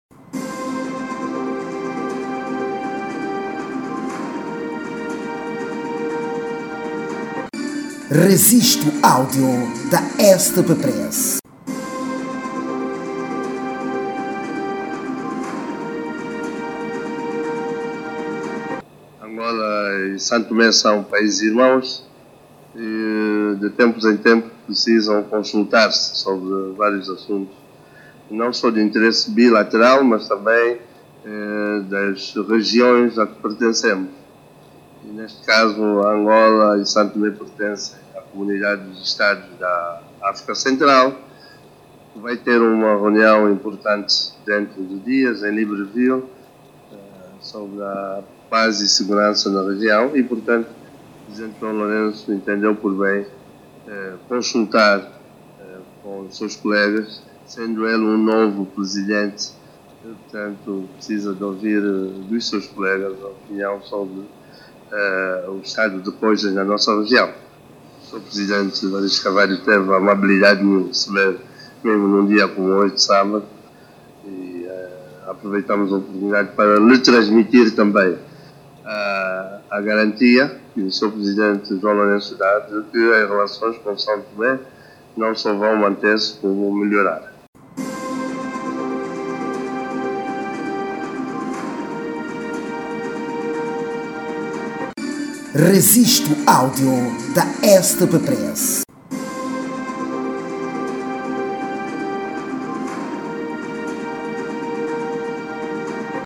Em declarações a imprensa, o ministro angolano disse que “ o presidente João Lourenço entendeu, por bem consultar com os seus colegas, sendo ele um novo presidente, as opiniões sobre o estado de coisas da nossa região”, a CEEAC, face a próxima cimeira de Libreville, Gabão.